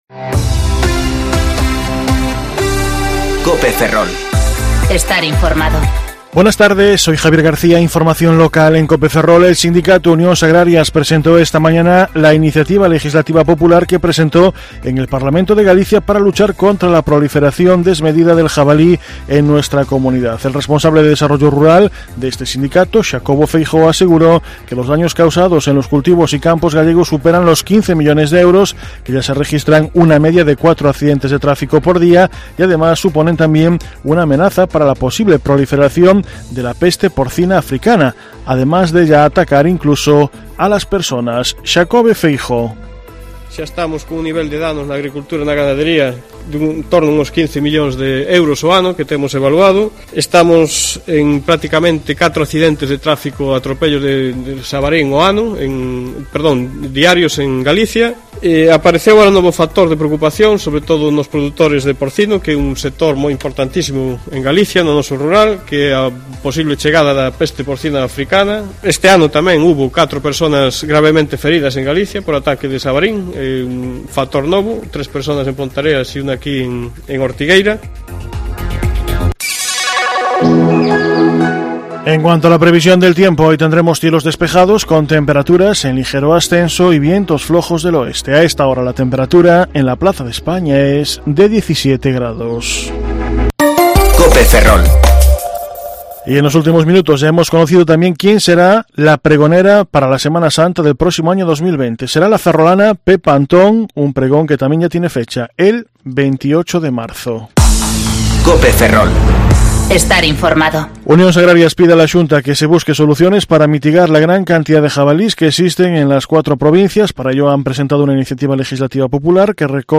Informativo Mediodía Cope Ferrol 11/10/2019 (De 14.20 a 14.30 horas)